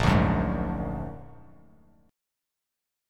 Bb+7 chord